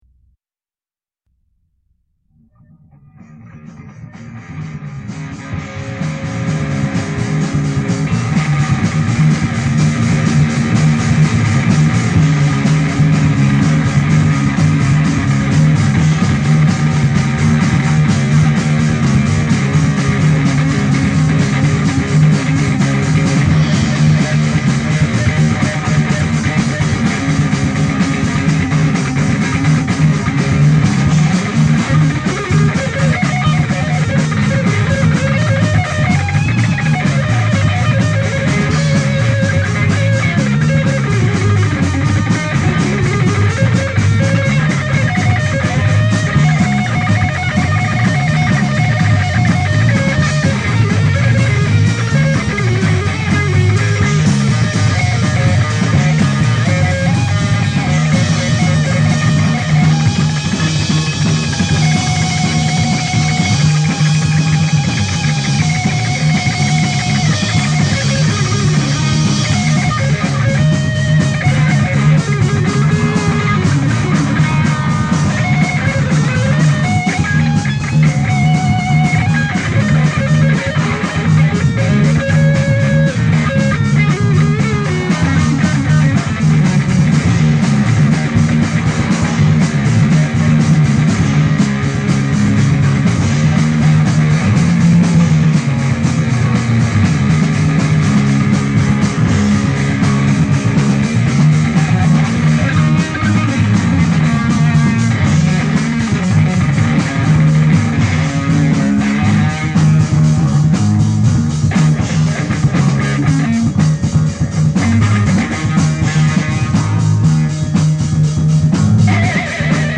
drums
guitar
bass
tamborine